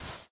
minecraft / sounds / dig / sand4.ogg
sand4.ogg